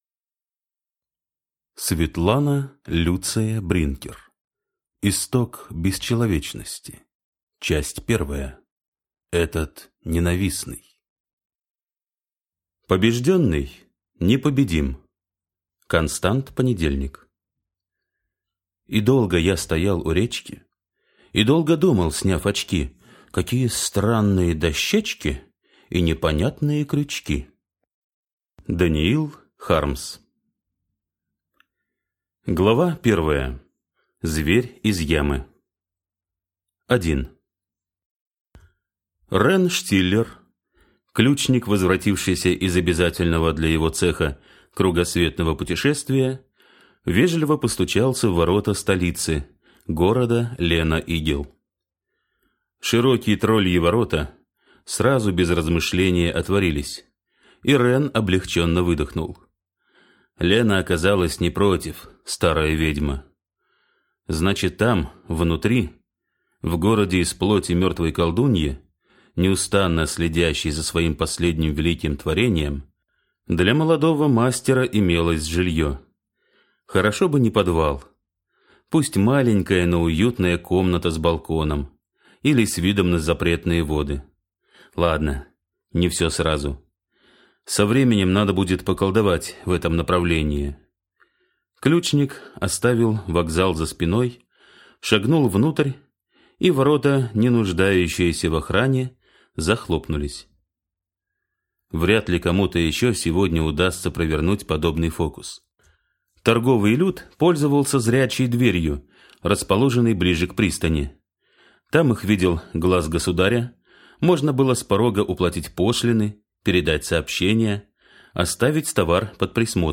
Аудиокнига Исток бесчеловечности. Часть 1. Этот ненавистный | Библиотека аудиокниг